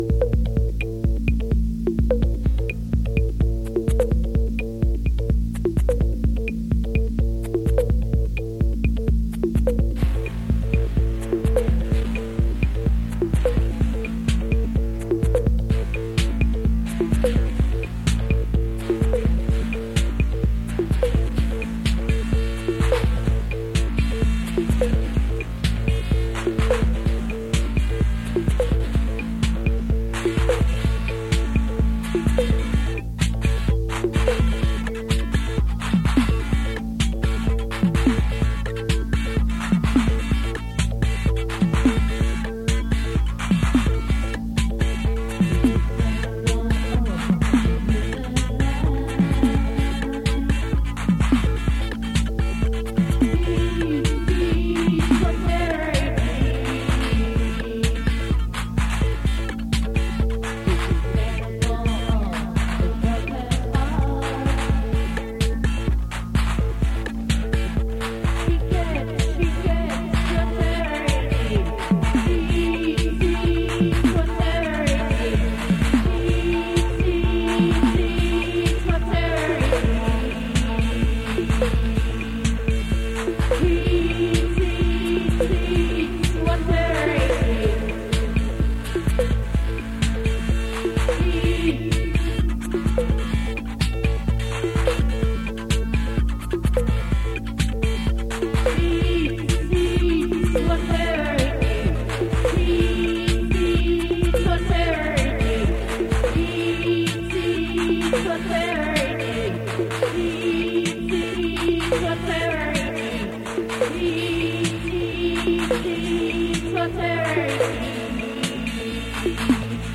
proto-techno